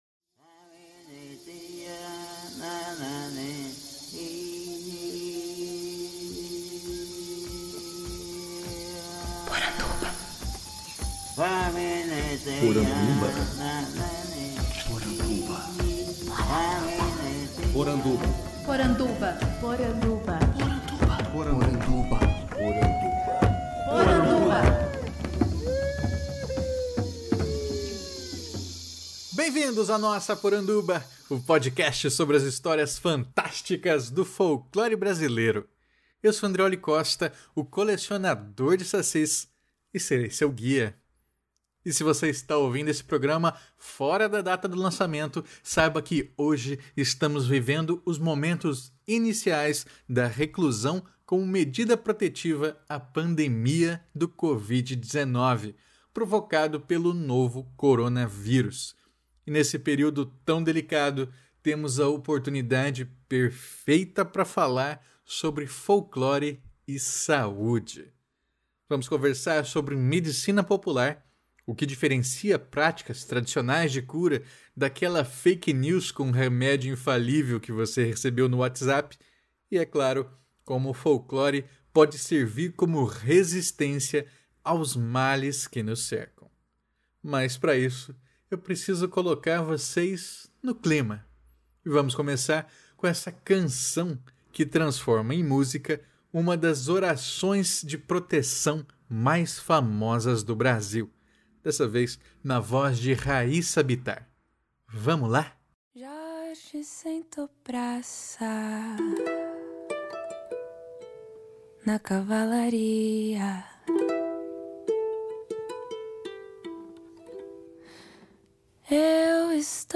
E no programa de hoje, gravado em plena pandemia do coronavírus, tomo um tempo para refletir sobre folclore e saúde nos seus mais variados aspectos: o imaginário das doenças, o valor da medicina popular, o que diferencia práticas tradicionais de cura daquela fake news que você recebeu no whatsapp e, é claro, como folclore pode servir como resistência aos males que nos cercam nessa quarentena.